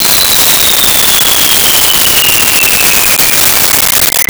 Bomb Fall 01
Bomb Fall 01.wav